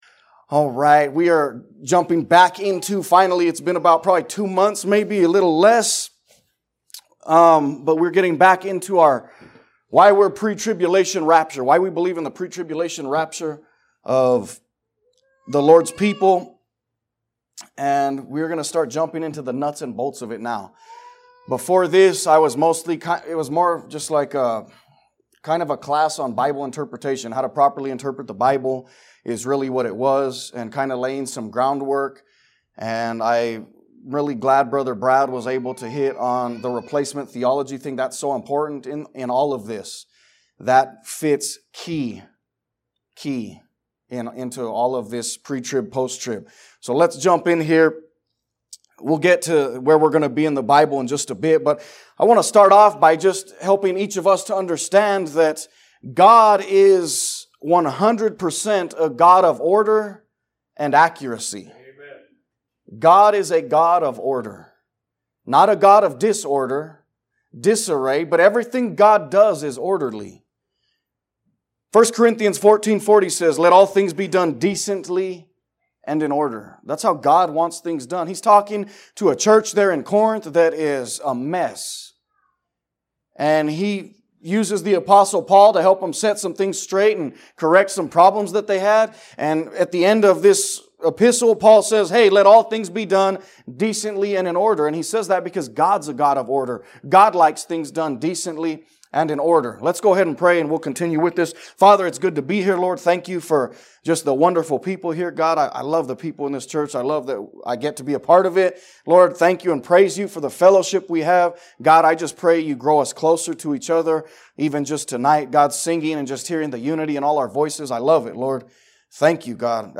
Sermons | Liberty Baptist Church